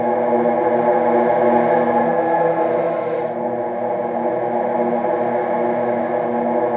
Index of /90_sSampleCDs/Spectrasonic Distorted Reality 2/Partition G/01 DRONES 1